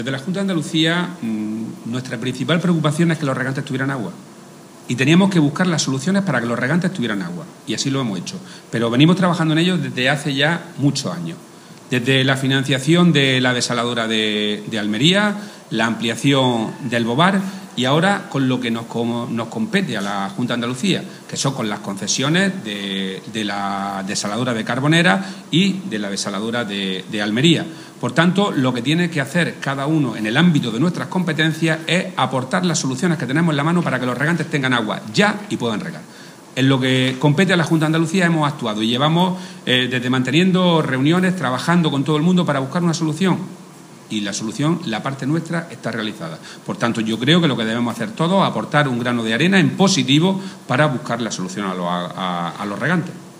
Declaraciones de Rodrigo Sánchez sobre recursos hídricos